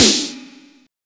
TOM01.wav